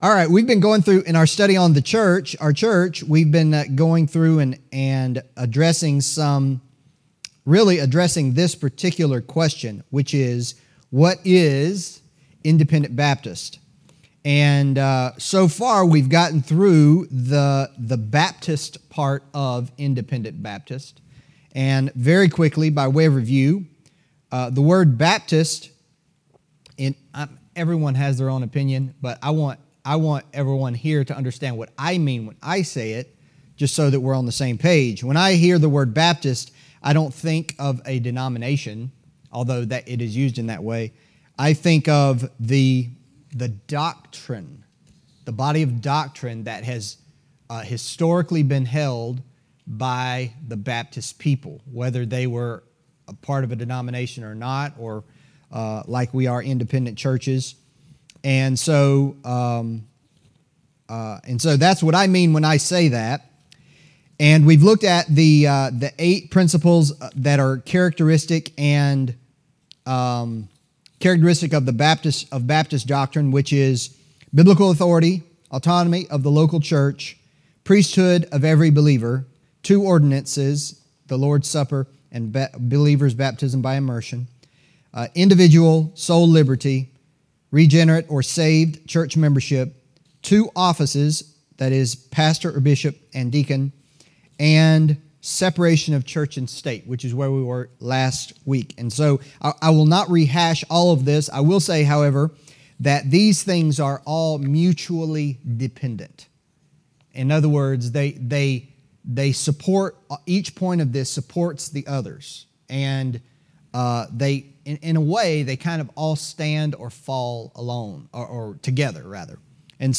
Adult Sunday School